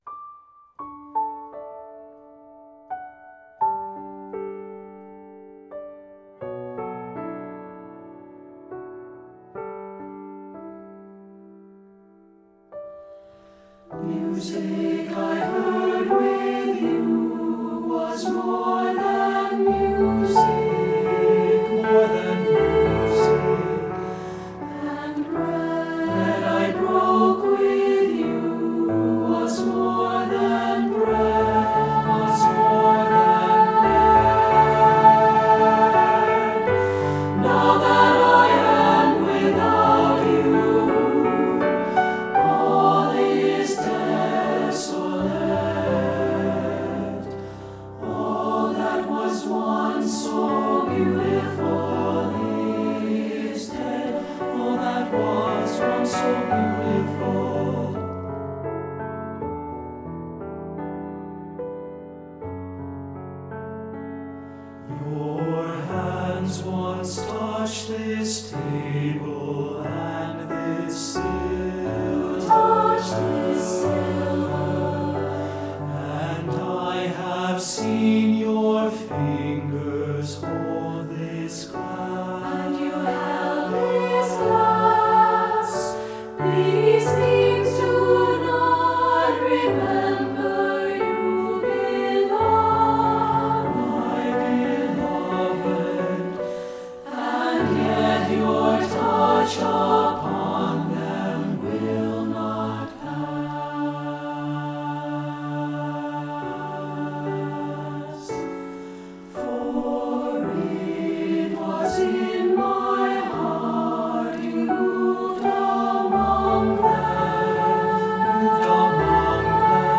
secular choral
SATB recording